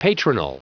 Prononciation du mot patronal en anglais (fichier audio)
Prononciation du mot : patronal